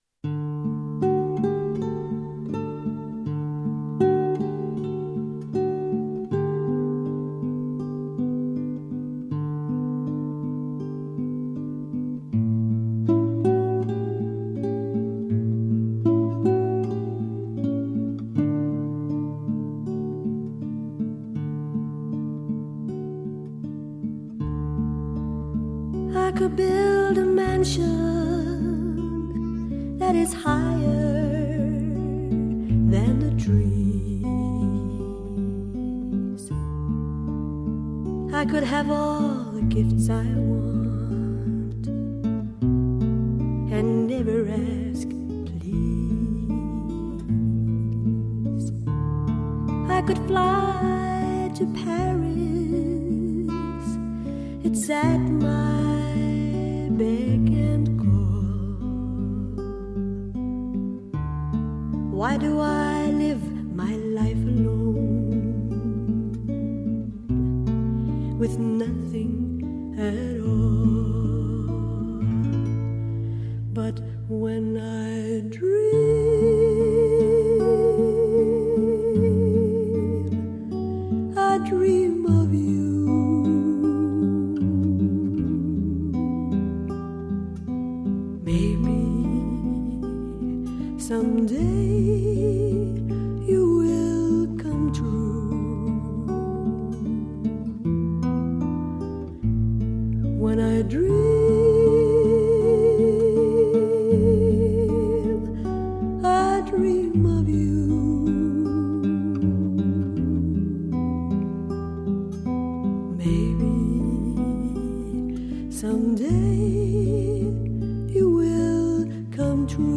她的歌声甜如蜜糖，最擅长板的抒情歌曲。